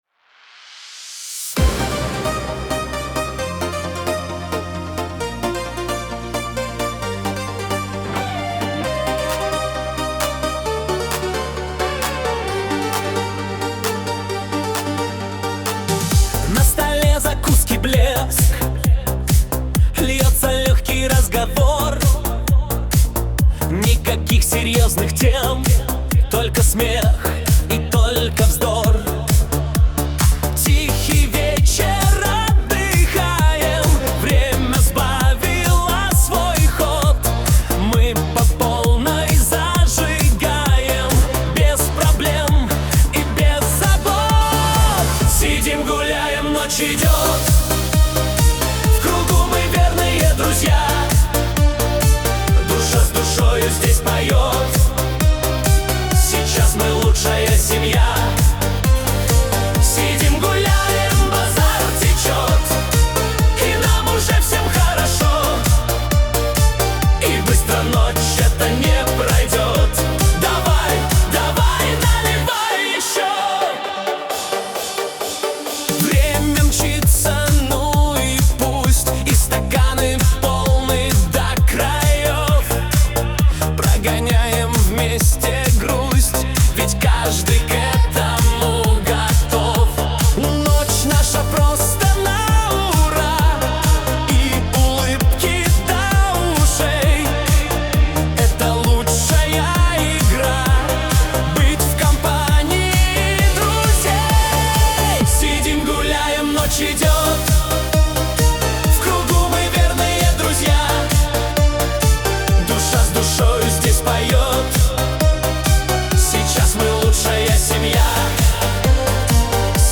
Душевные песни